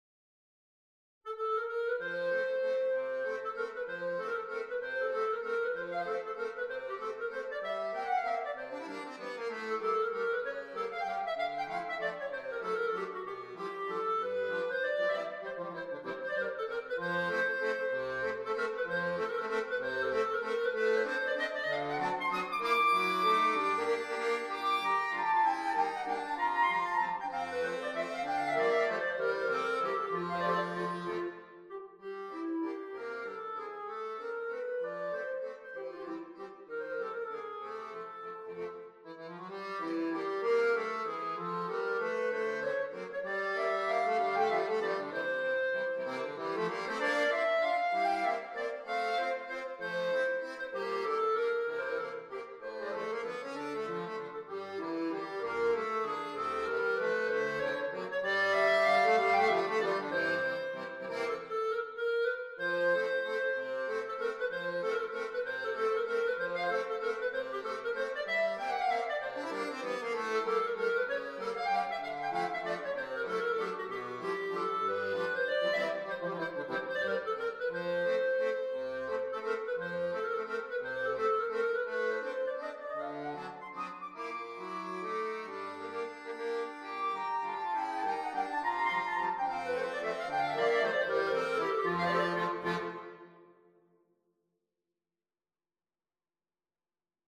Music / Classical
accordion clarinet